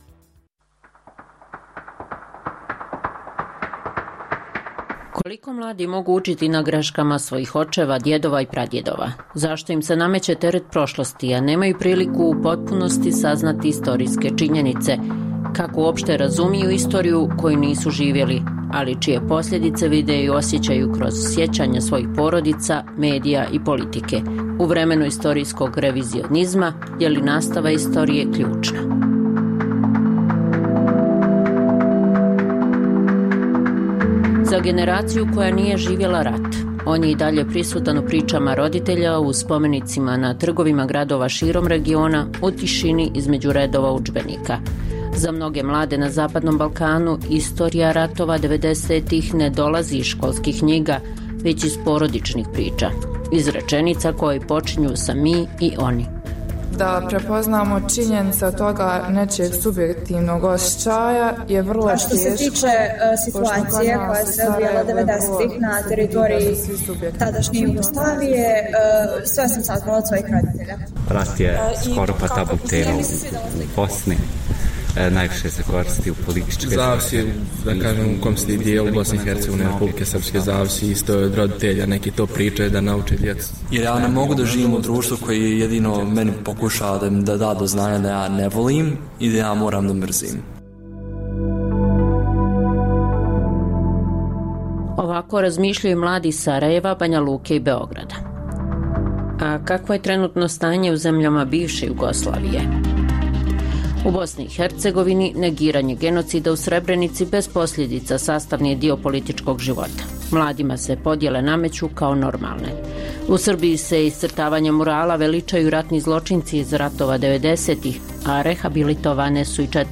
Emisija u kojoj dublje istražujemo aktuelne događaje koji nisu u prvom planu kroz intervjue, analize, komentare i reportaže.